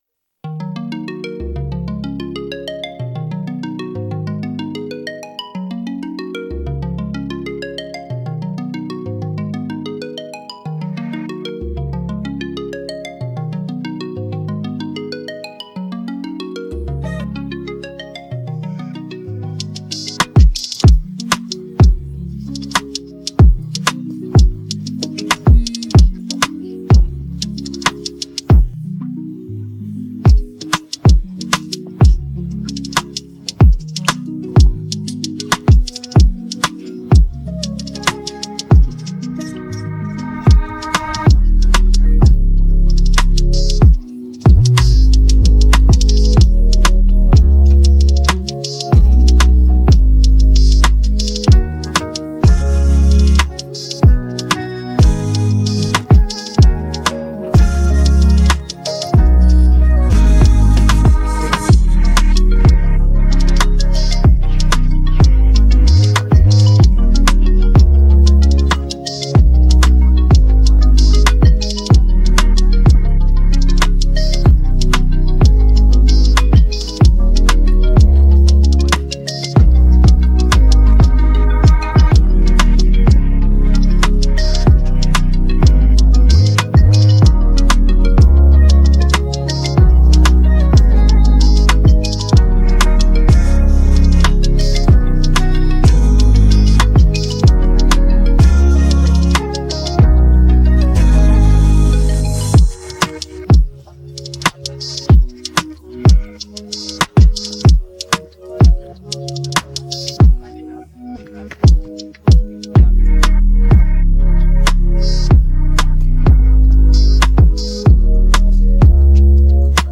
Afro pop Afrobeats